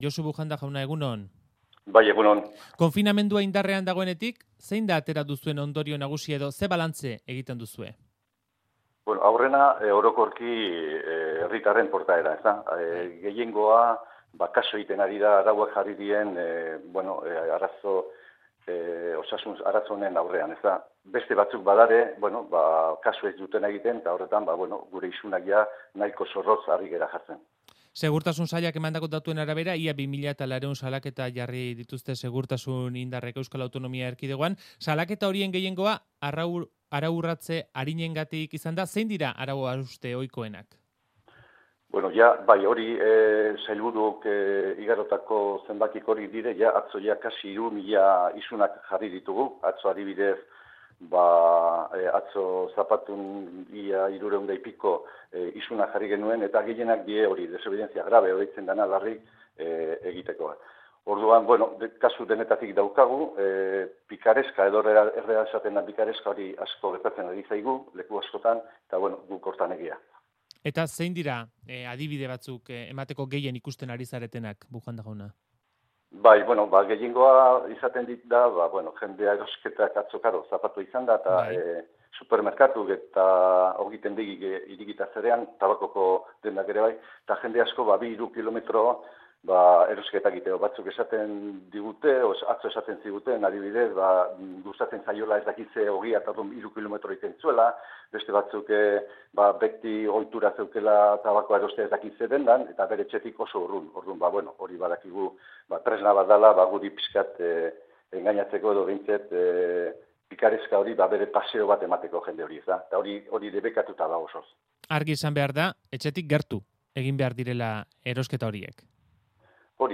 Audioa: Entzuleen galderei erantzun die Ertzaintzako buruak Euskadi Irratian.